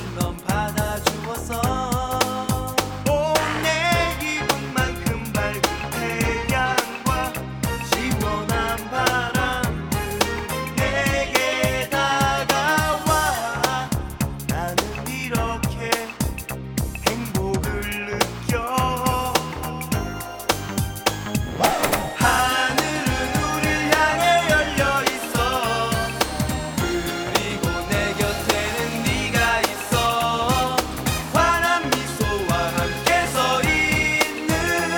K-Pop Pop Hip-Hop Rap Korean Hip-Hop
Жанр: Хип-Хоп / Рэп / Поп музыка